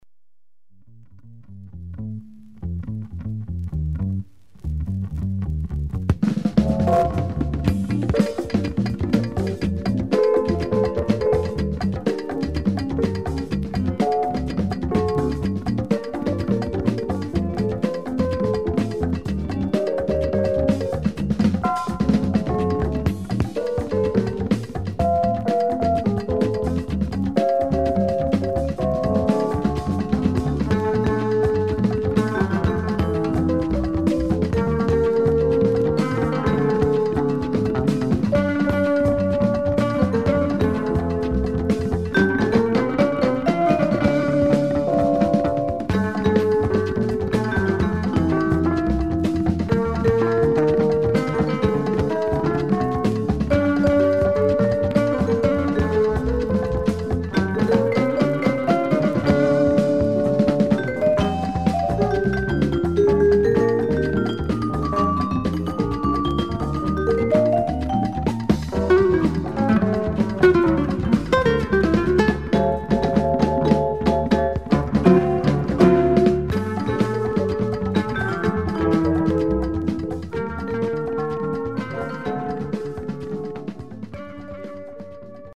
1980年前後の録音。
JAZZ FUSION色の強いアルバムで